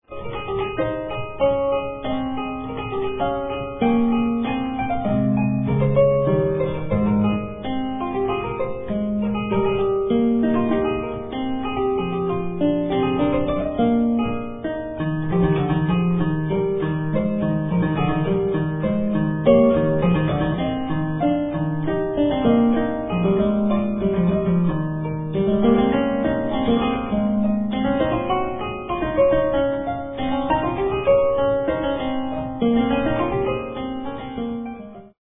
performed on the gut-strung lautenwerck